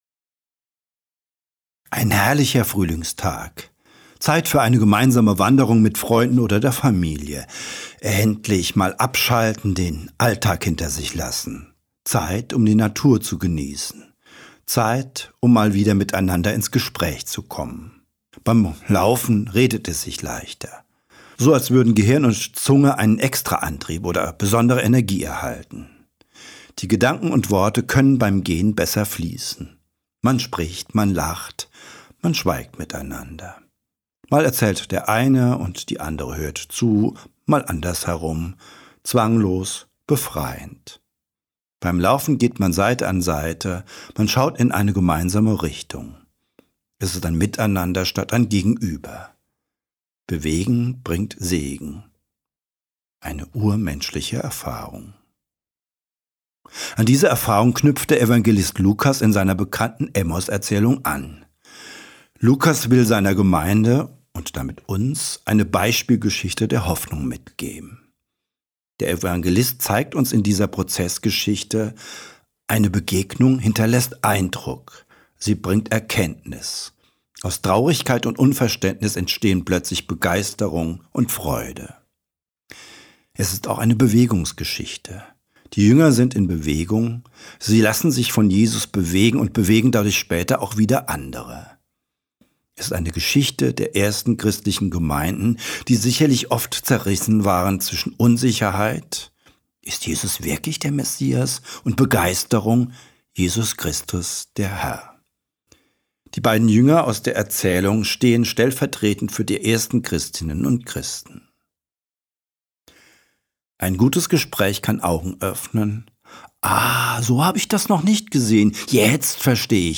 Ostermontag-2025-Predigt-5.mp3